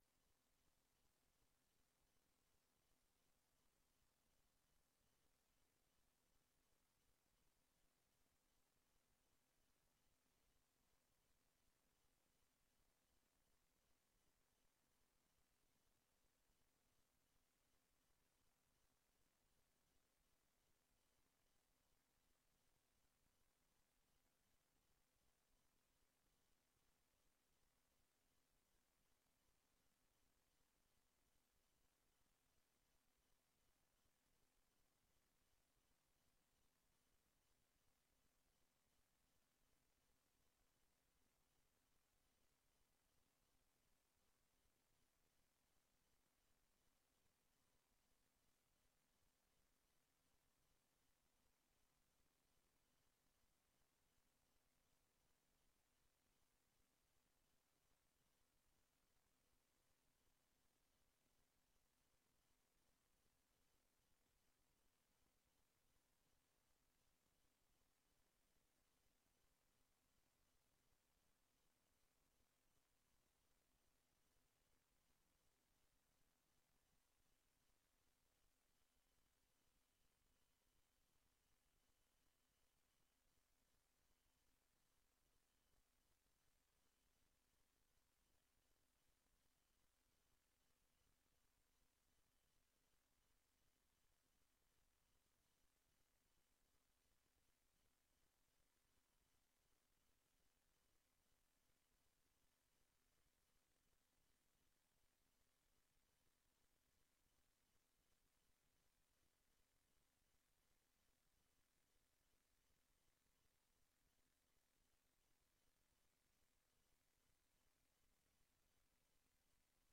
Raadsinformatie avond in De Beeck, Molenweidtje 2, 1862 BC Bergen.